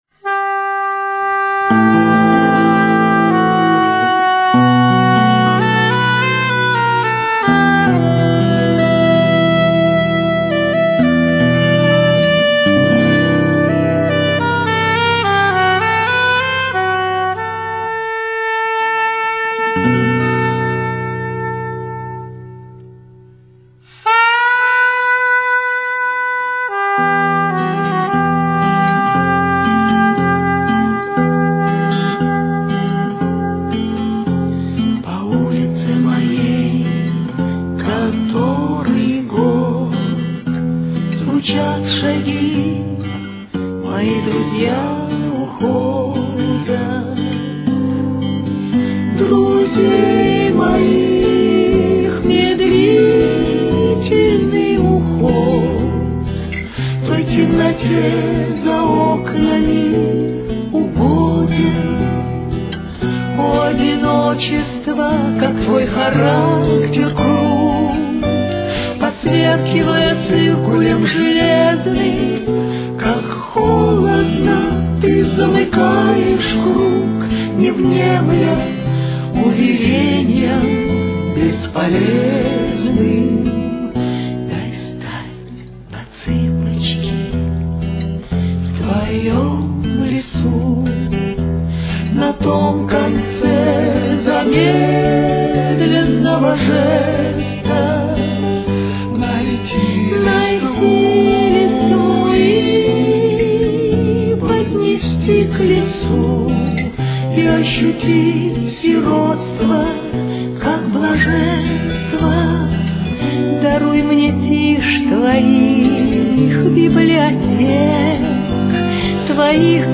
Тональность: Ми минор. Темп: 118.